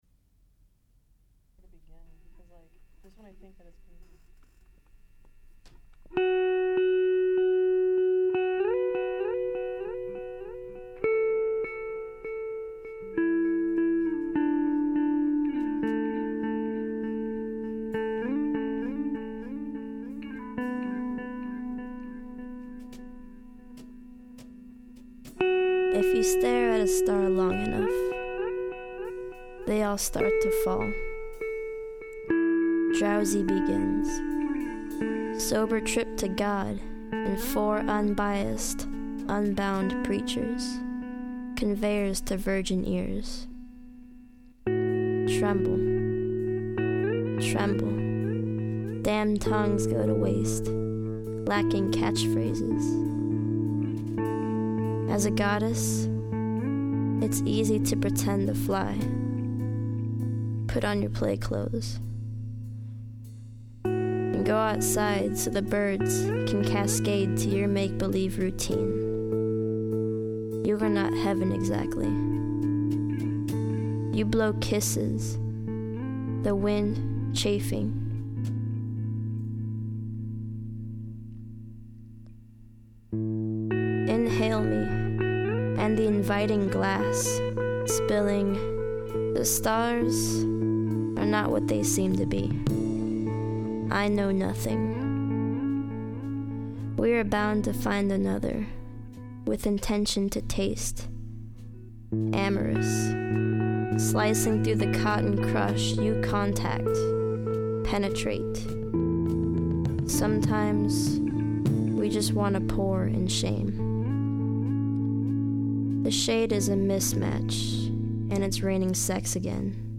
Spoken Word 1.mp3